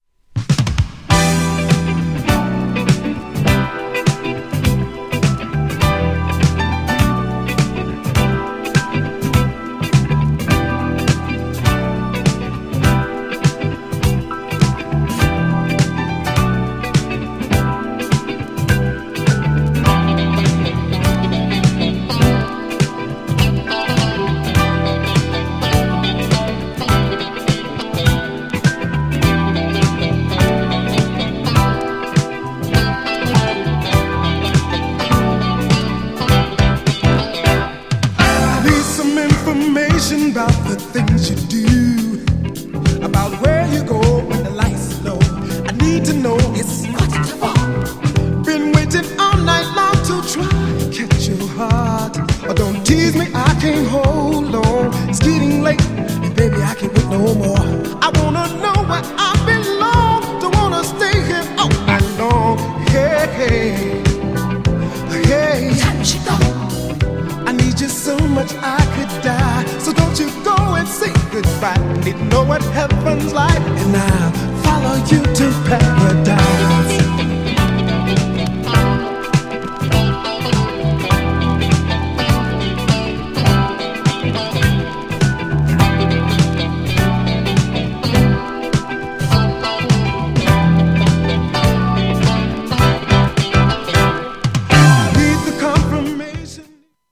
チョッパーベースが印象的な世界的ヒット曲。
GENRE Dance Classic
BPM 116〜120BPM